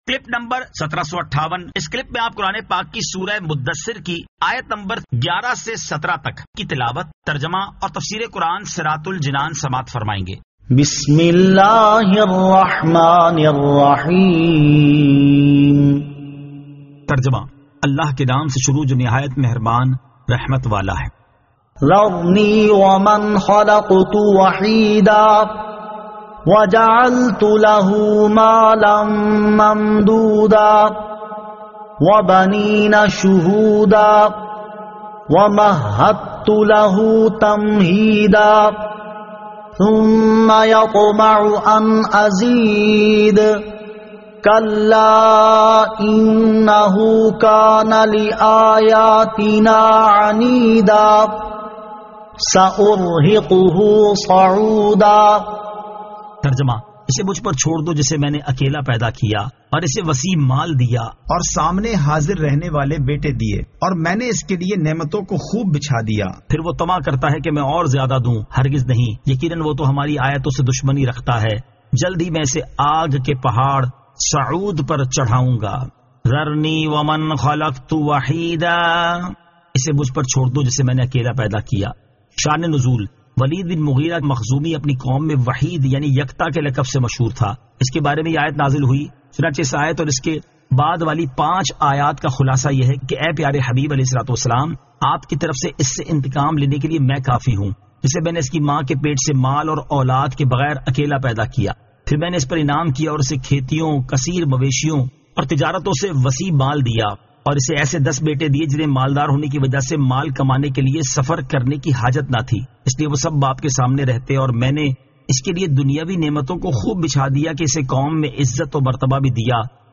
Surah Al-Muddaththir 11 To 17 Tilawat , Tarjama , Tafseer